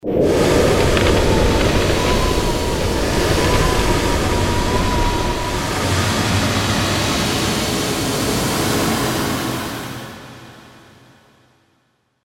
freezing_field.mp3